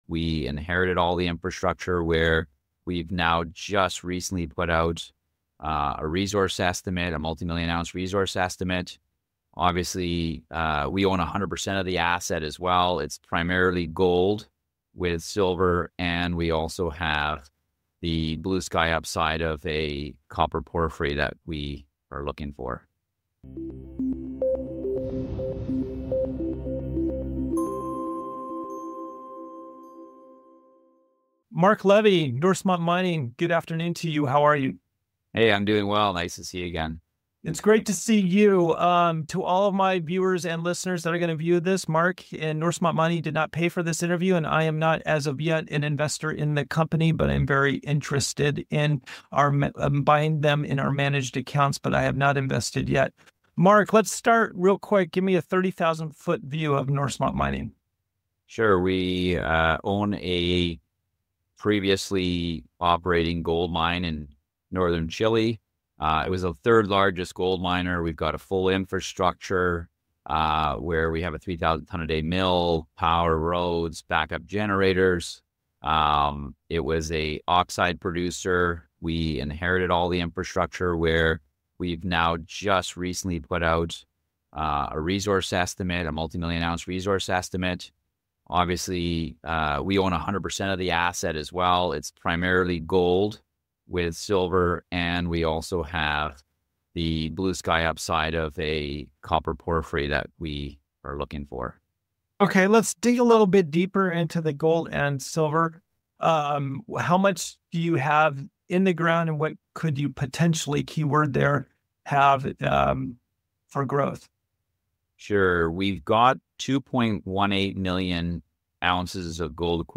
Exclusive Interview with Norsemont Mining: Gold & Silver Prospects in Chile - Natural Resource Stocks